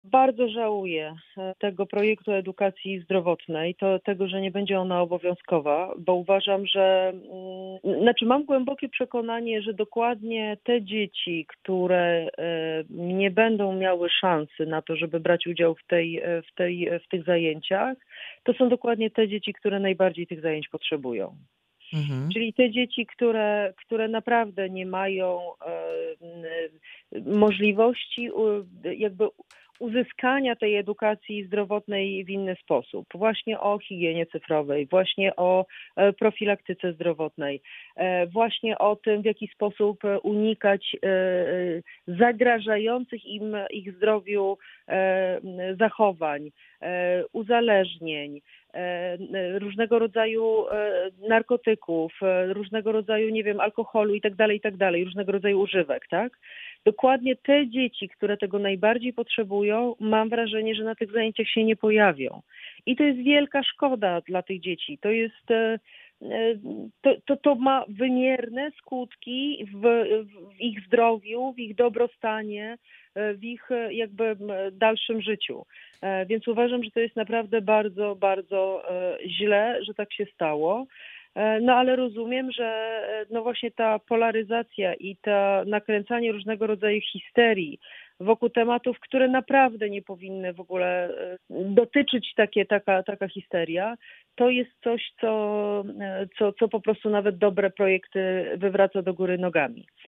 Wiceminister Joanna Mucha w audycji „Poranny Gość” zapowiedziała start kampanii Szymona Hołowni.